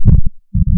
描述：产生的声音为正弦波852Hz 0,8dB，持续0,5s我在开始和结束时修改了声音的曲线。
Tag: 摩擦 大声的 吵闹 摩擦